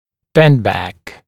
[‘bendˌbæk][‘бэндˌбэк]дистальный изгиб